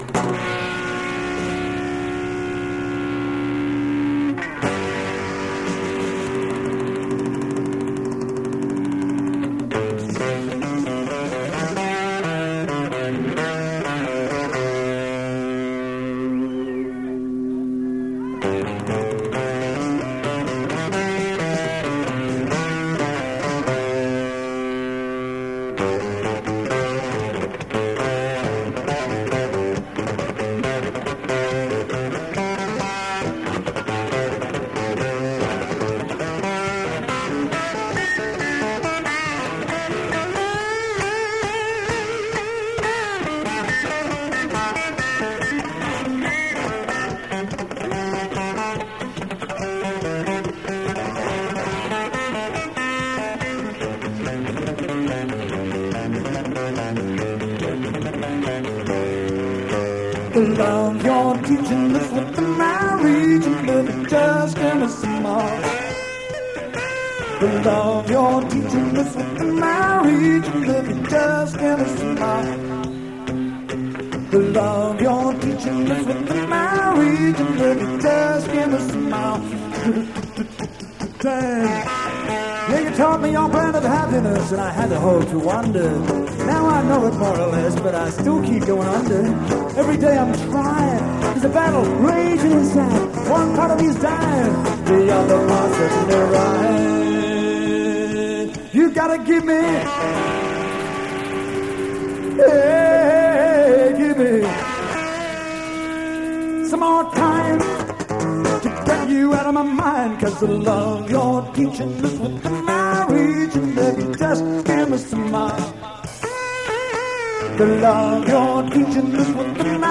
Guitar and Vocal
Flute
Electronic Drums
Fretless bass
Venue The Embassy Hotel Brisbane